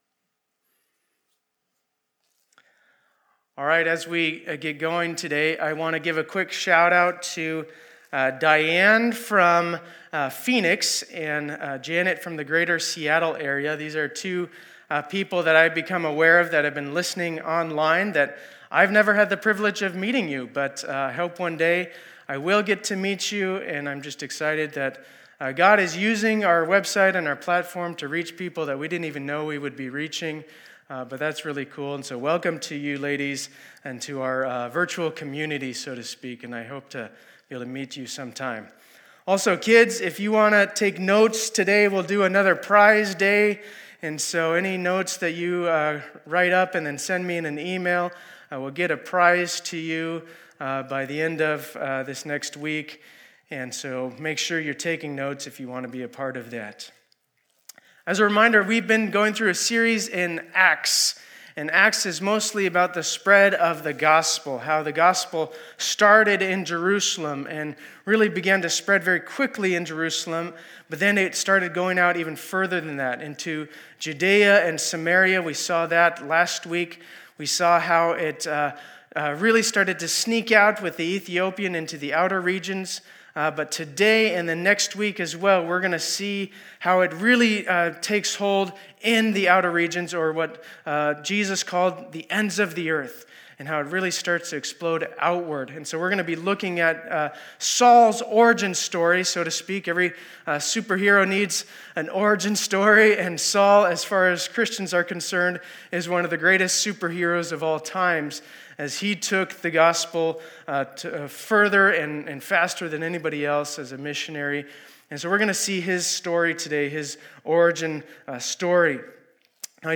2020-07-19 Sunday Service